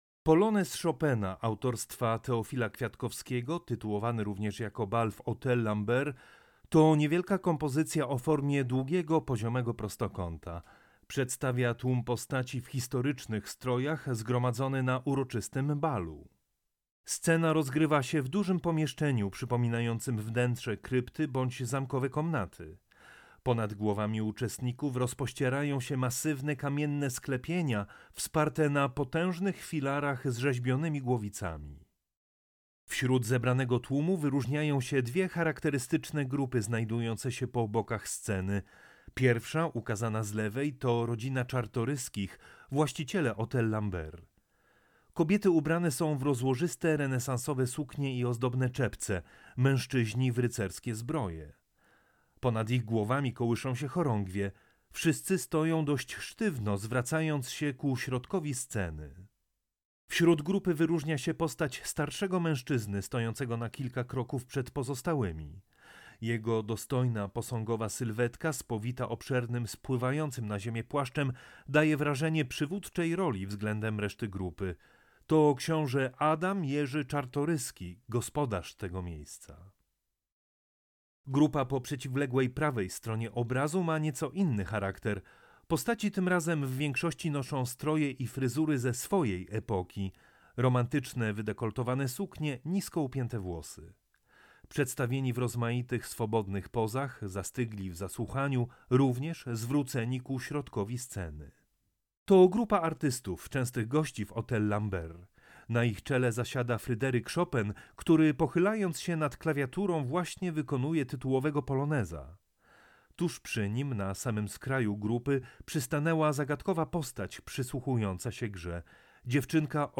AUDIODESKRYPCJA
AUDIODESKRYPCJA-Teofil-Kwiatkowski-Bal-w-Hotel-Lambert.mp3